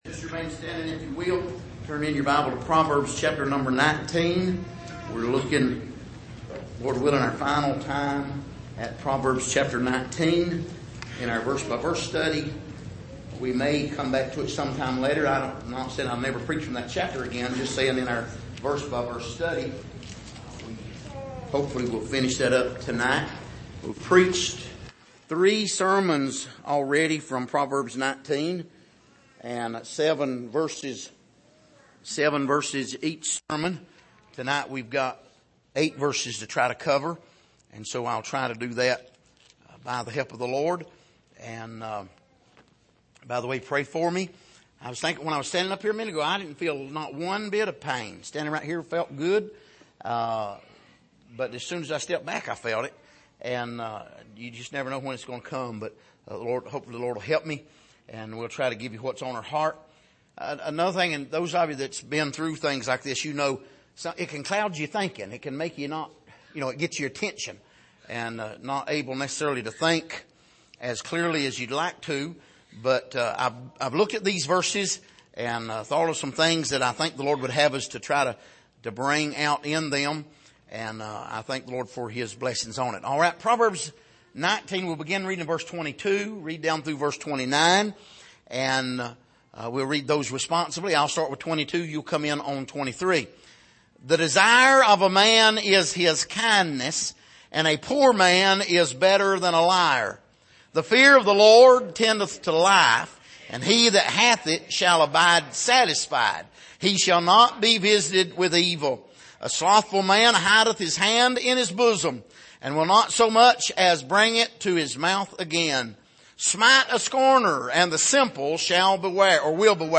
Passage: Proverbs 19:22-29 Service: Sunday Morning